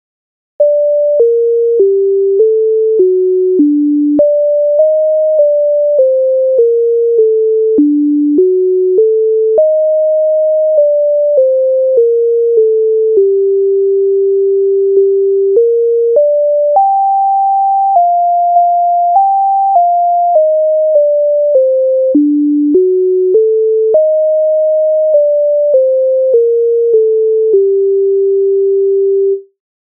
Місяць на небі Українська народна пісня
Ukrainska_narodna_pisnia_Misiats_na_nebi.mp3